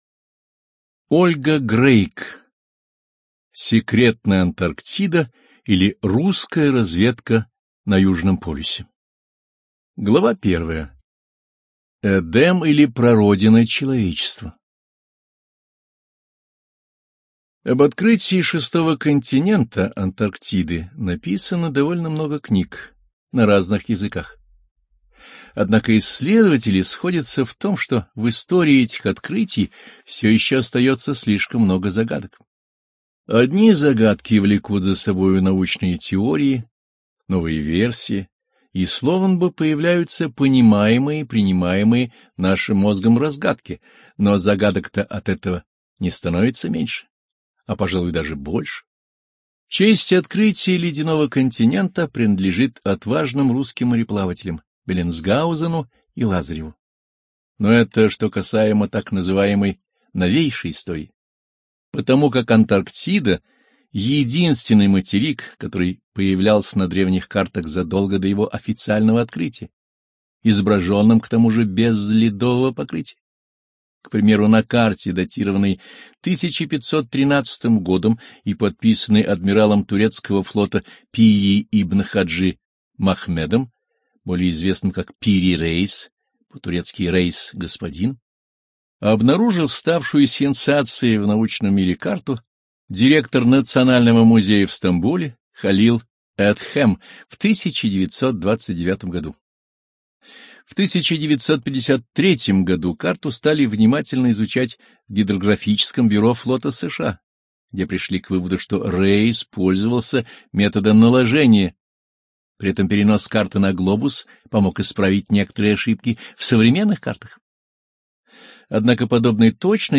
Студия звукозаписиАрдис